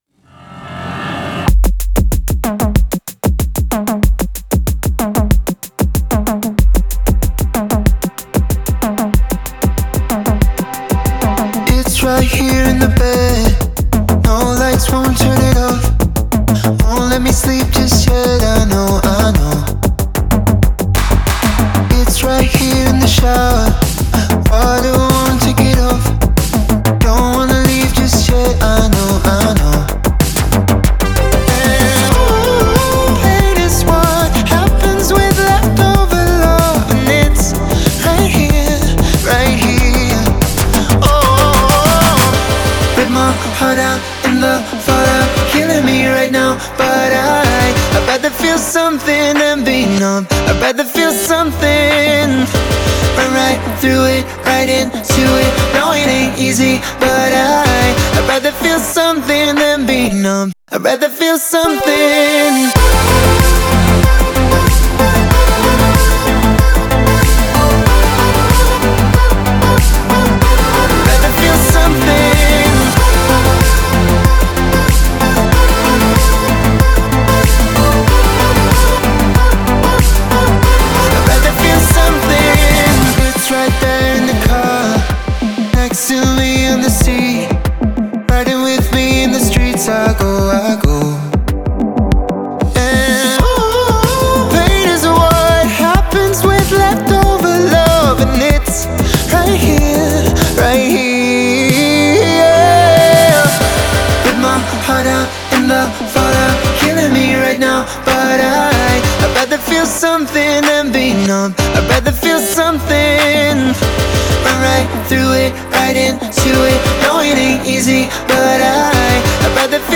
2020 RETROWAVE DOWNLOAD TELEGRAM